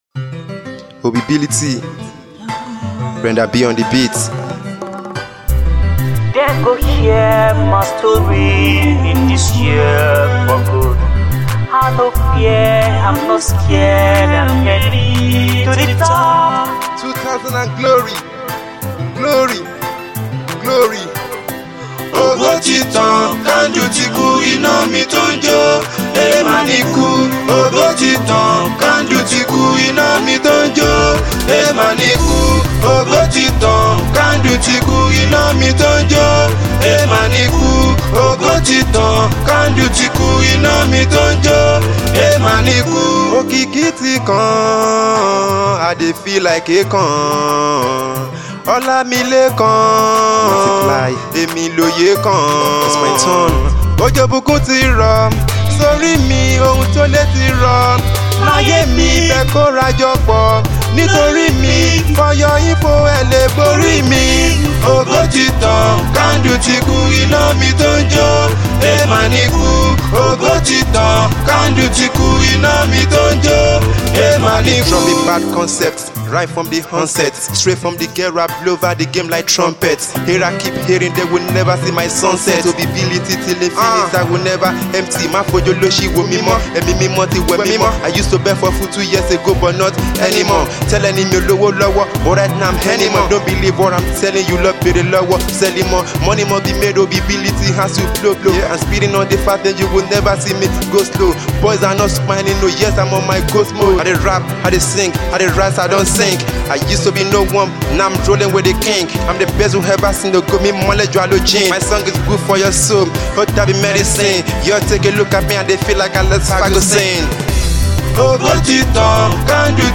Indigenous Pop
He Raps n sings using Yoruba and English language perfectly.